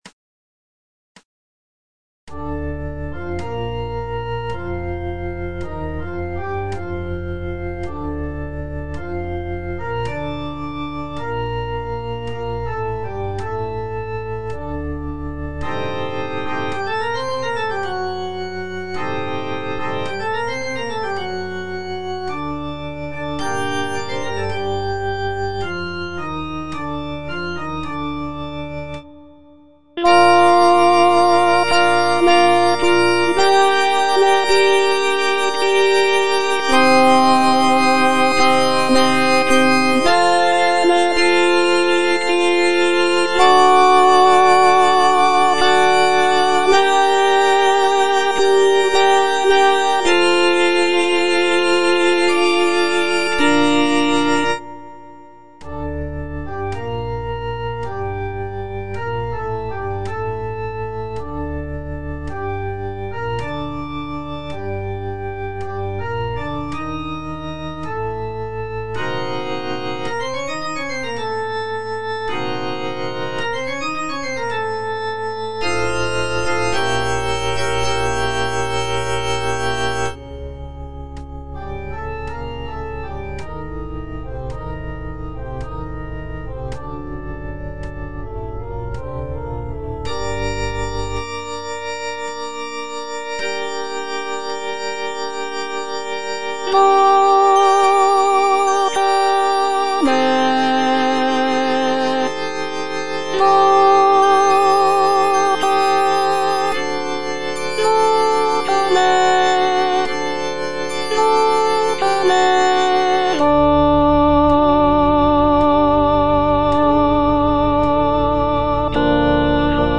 F. VON SUPPÈ - MISSA PRO DEFUNCTIS/REQUIEM Confutatis (alto II) (Voice with metronome) Ads stop: auto-stop
The piece features lush harmonies, soaring melodies, and powerful choral sections that evoke a sense of mourning and reverence.